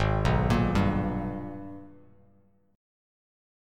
G#mM9 chord